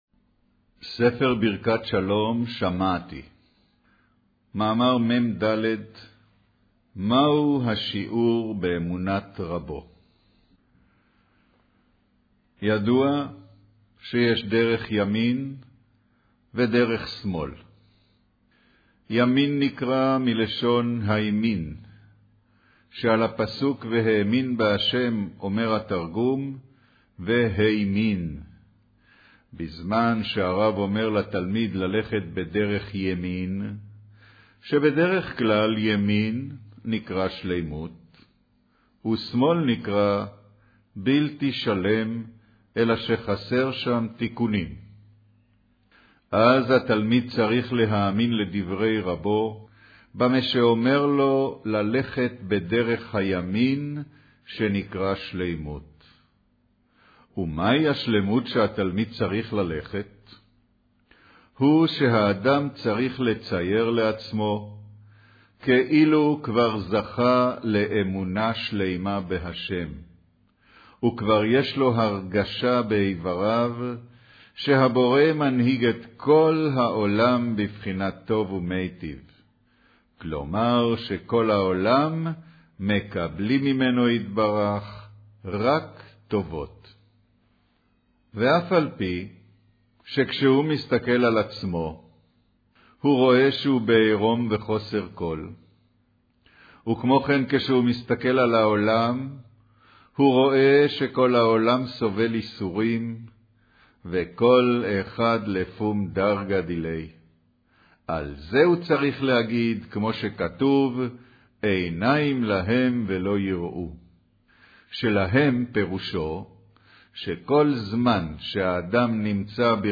אודיו - קריינות מאמר מהו השיעור באמונת רבו